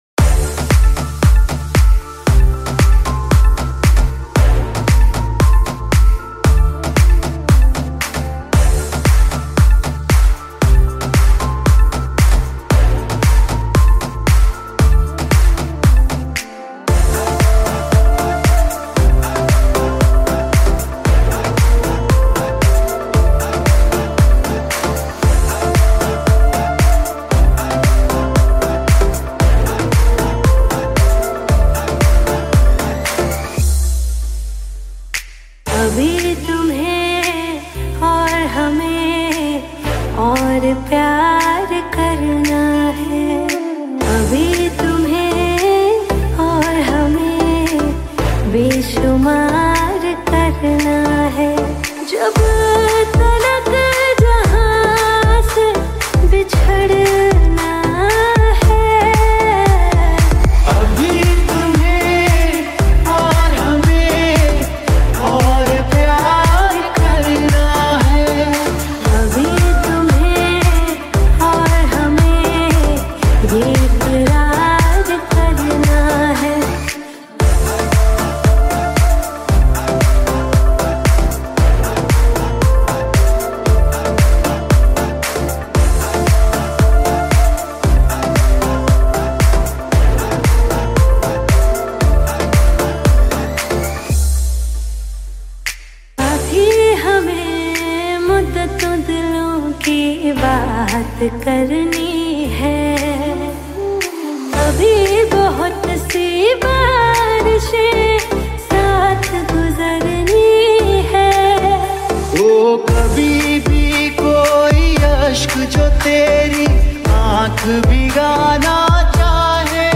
Remix Mashup